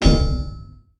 goldenpig_hit_01.ogg